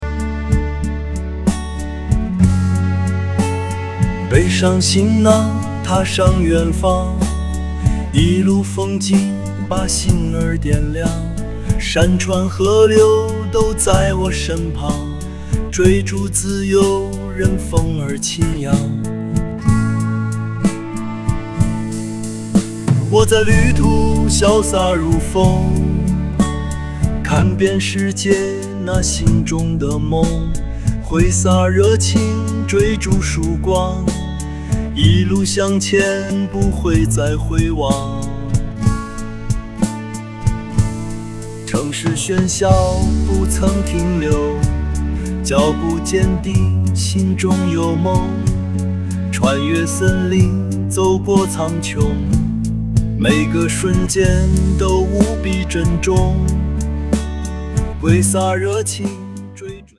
【AI工具】我用AI工具2分钟免费创作了一首歌曲，还挺好听